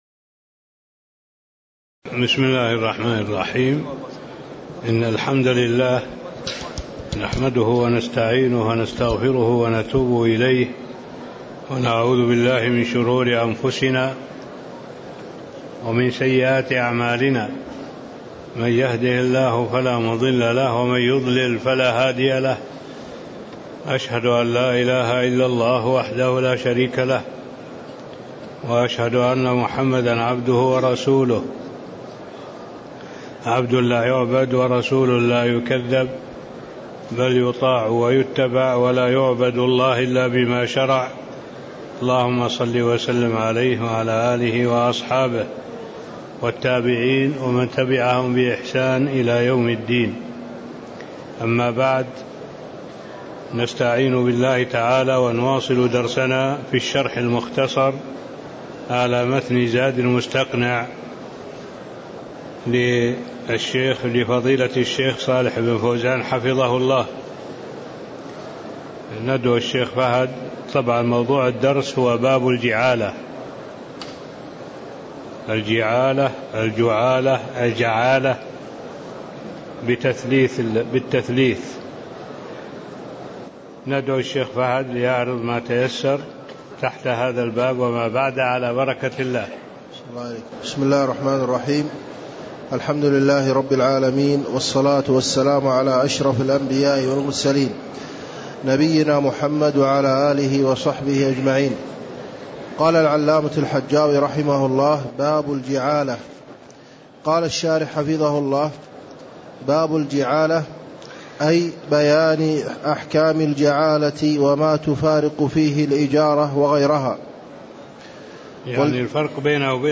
تاريخ النشر ٩ جمادى الأولى ١٤٣٥ هـ المكان: المسجد النبوي الشيخ: معالي الشيخ الدكتور صالح بن عبد الله العبود معالي الشيخ الدكتور صالح بن عبد الله العبود باب الجعالة (17)